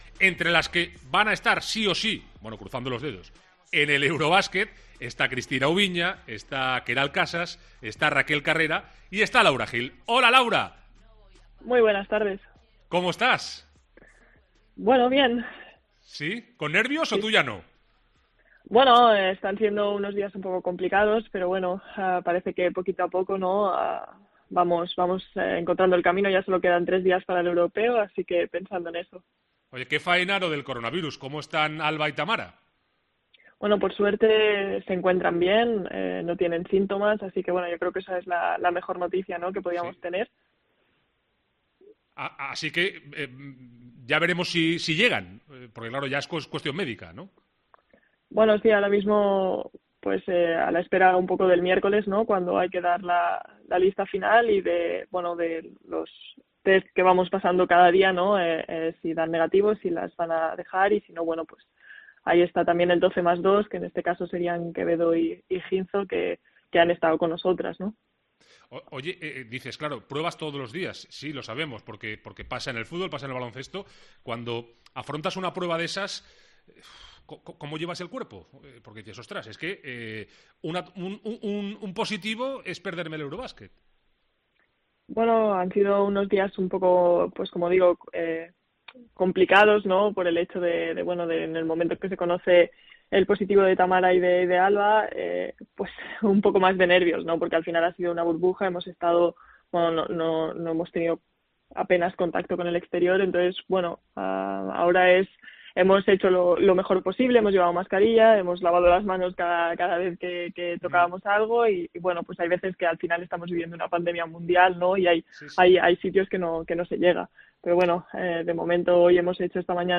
ENTREVISTA COPE
AUDIO. Entrevista a Laura Gil en Los Lunes al VAR